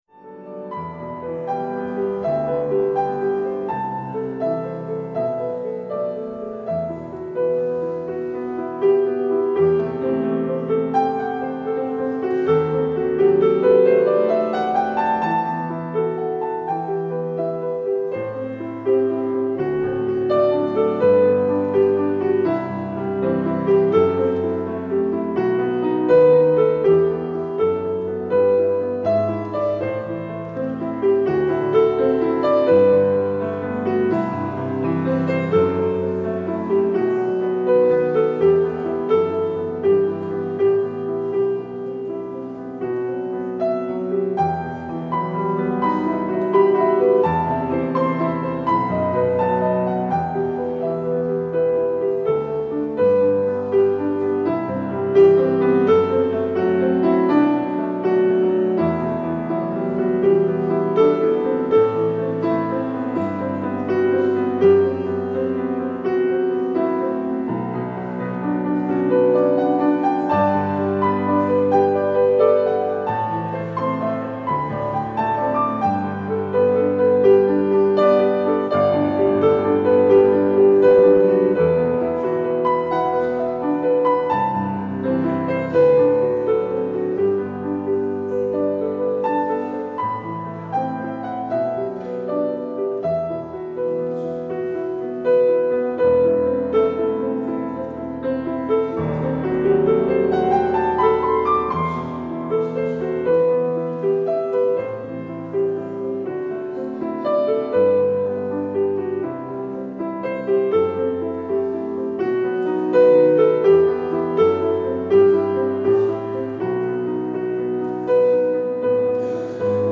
De voorganger vandaag is Prof. Dr. Antoine Bodar (Priester en hoogleraar Friezenkerk in Rome).
Luister deze kerkdienst hier terug:
Het openingslied is Gezang 138.
Als slotlied hoort u Ere zij God.